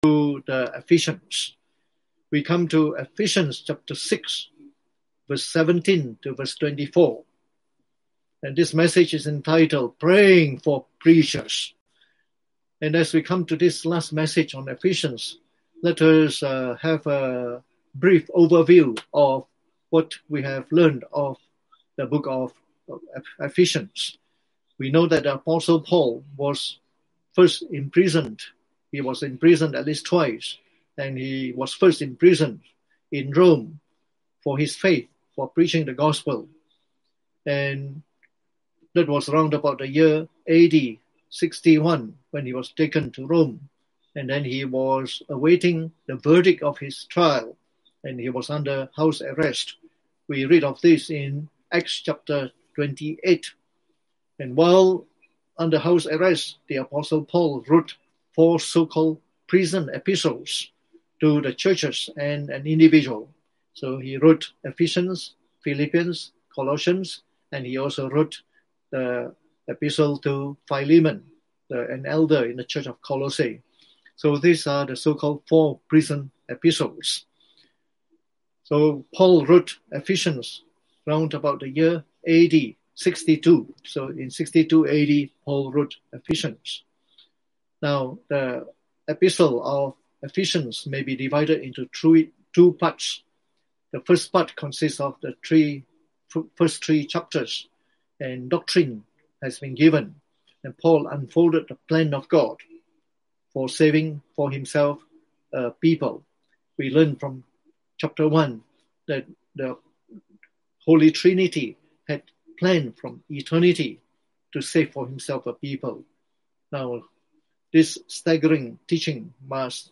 Preached on the 5th of July 2020.